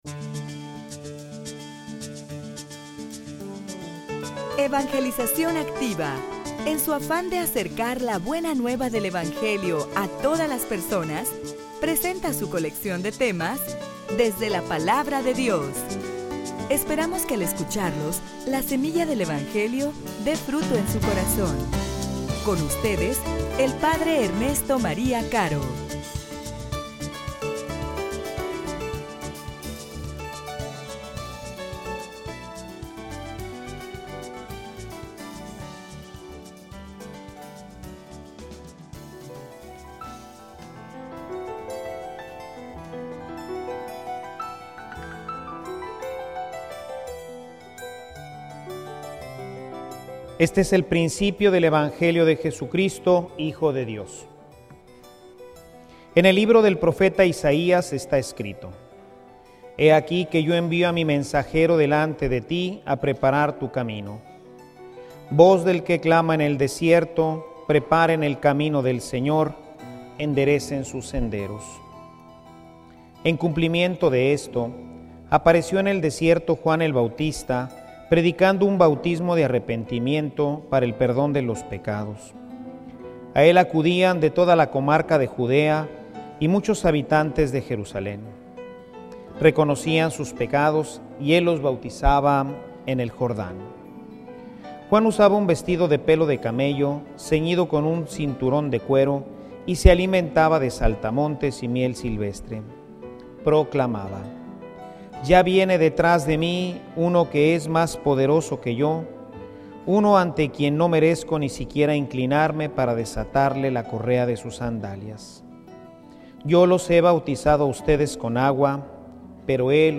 homilia_Dale_mantenimiento_a_tu_vida.mp3